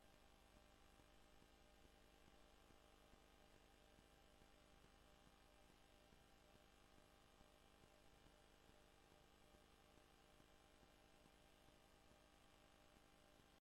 Gemeenteraad 05 november 2025 19:30:00, Gemeente Hof van Twente